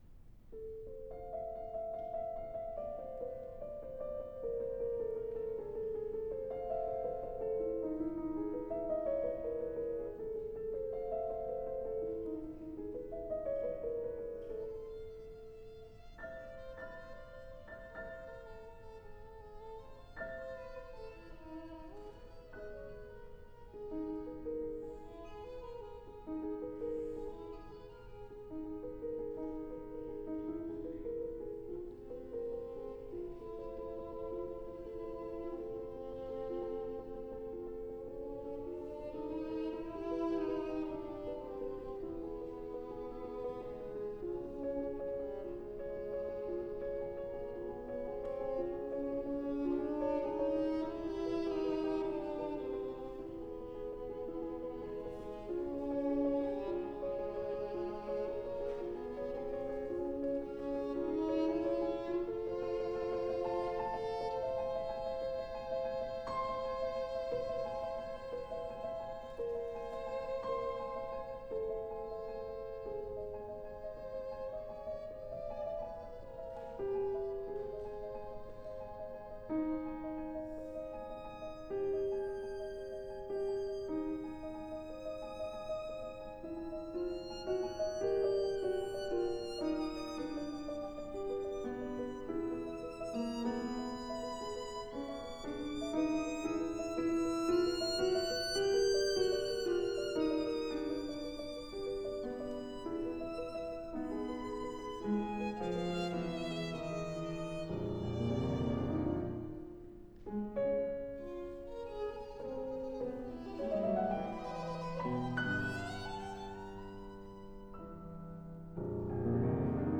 С.Прокофьев. Соната для скрипки и фортепиано № 1, части 3-4
Запись из Малого зала консерватории
рояль